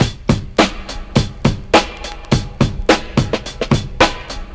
• 105 Bpm Drum Loop Sample A# Key.wav
Free drum loop - kick tuned to the A# note. Loudest frequency: 1276Hz
105-bpm-drum-loop-sample-a-sharp-key-8aR.wav